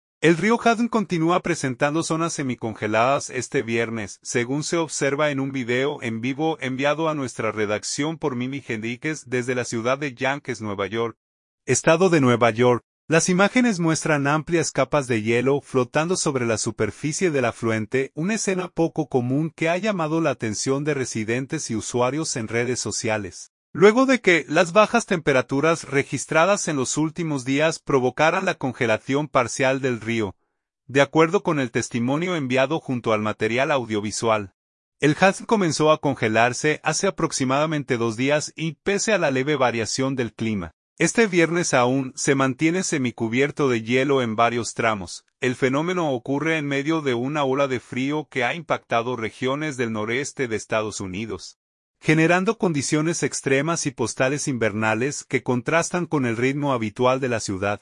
desde la ciudad de Yonkers Nueva York, estado de Nueva York.